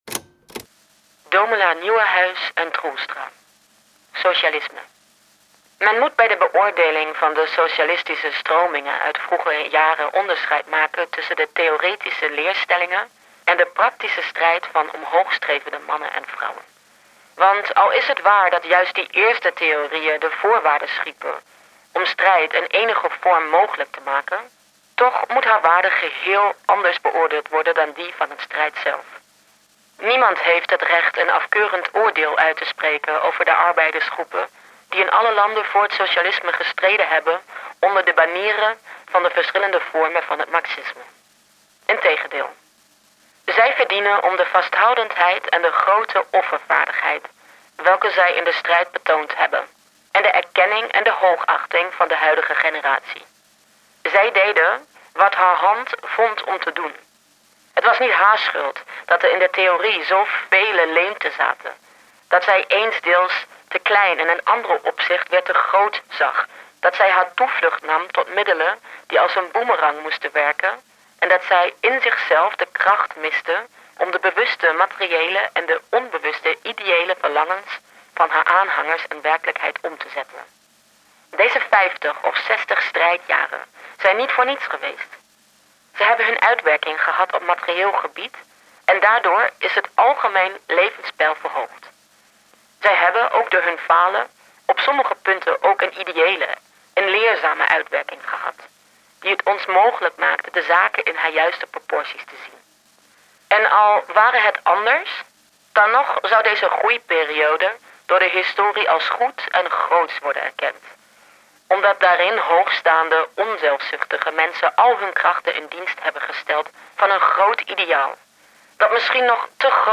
Recording: Killer Wave Studios, Hamburg · Editing: Kristen & Schmidt, Wiesbaden